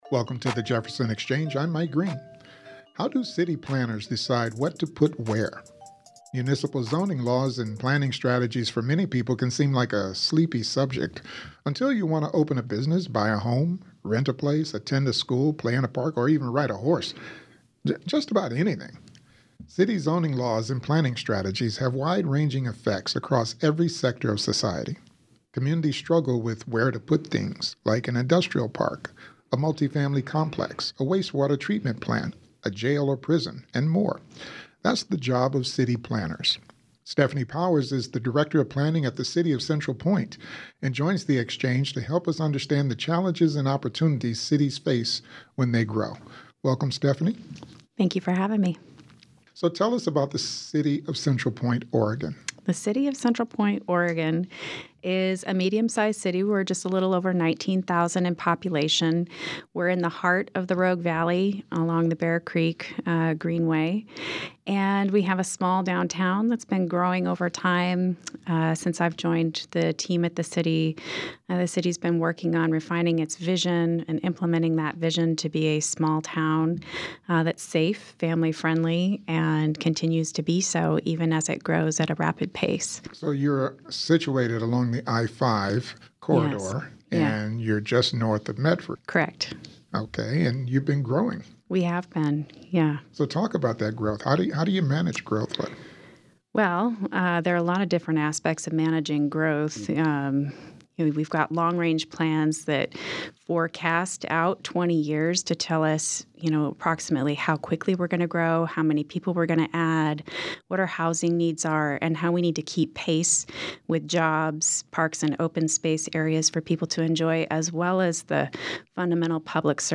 JPR's live interactive program devoted to current events and newsmakers from around the region and beyond.